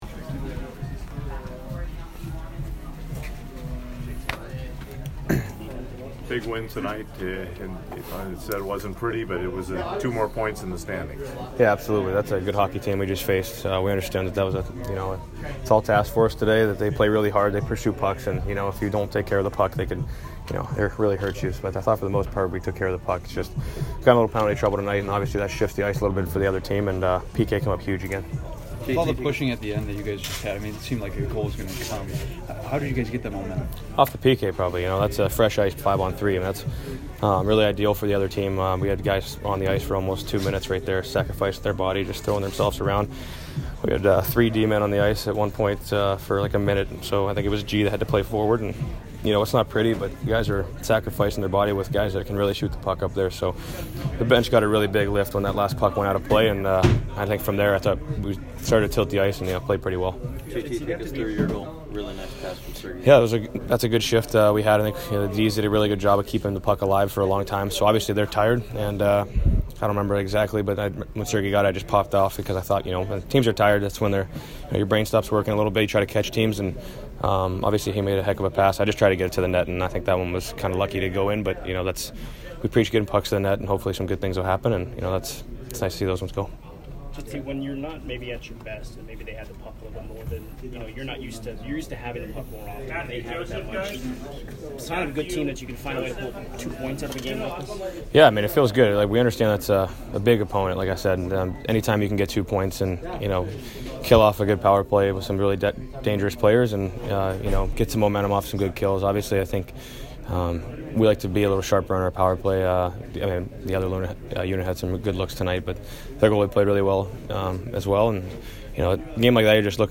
J.T. Miller post-game 11/8